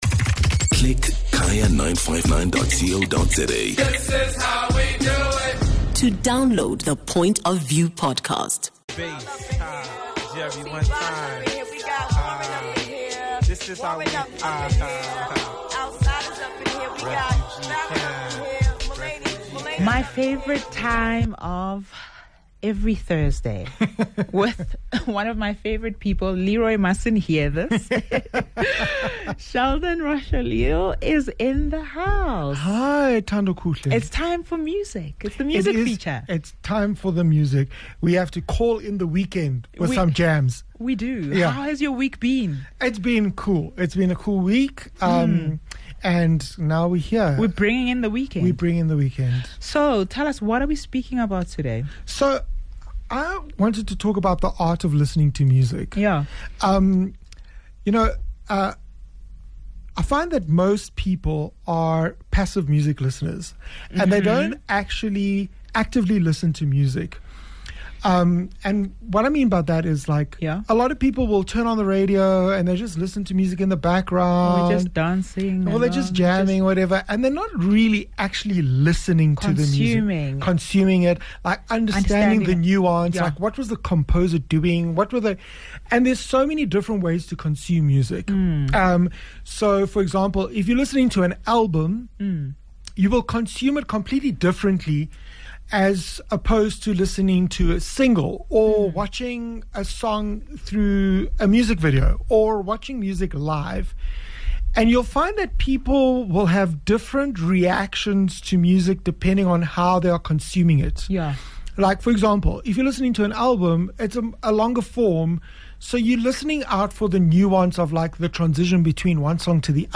Guest: Musicologist